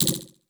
Message Bulletin Echo 3.wav